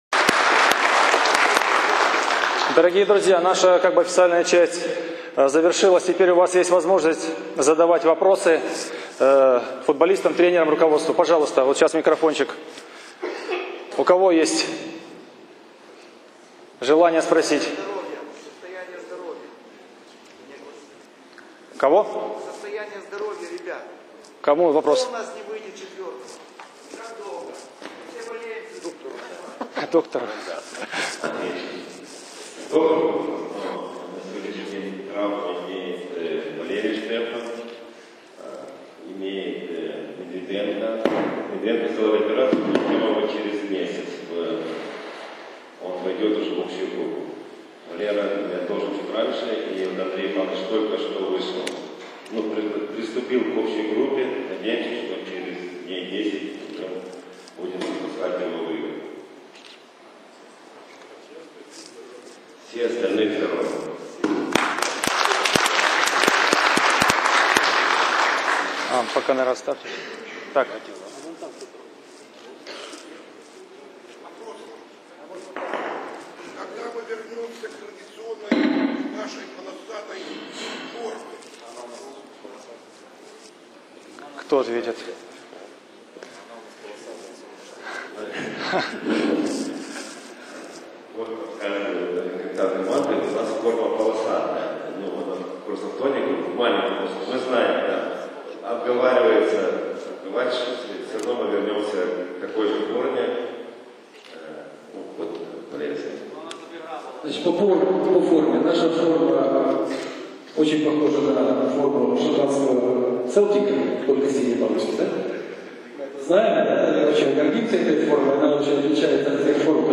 ФК «Севастополь» провел встречу с болельщиками
Общение в формате вопрос–ответ